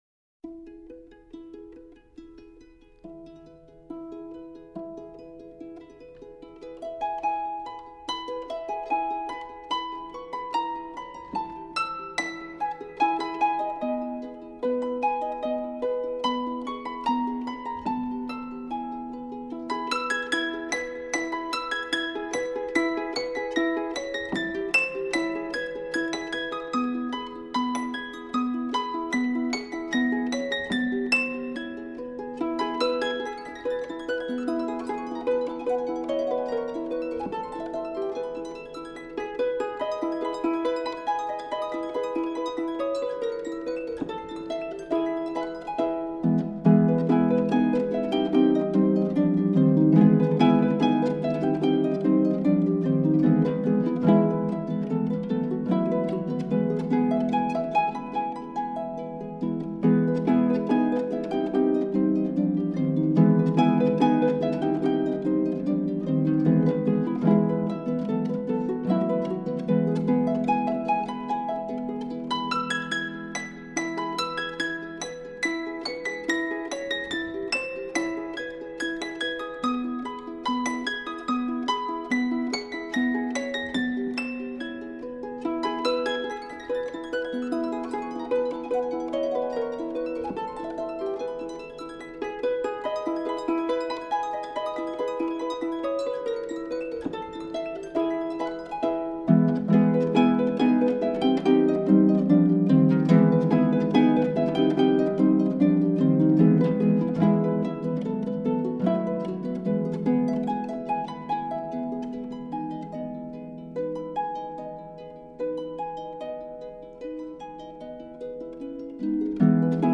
Edited for Harp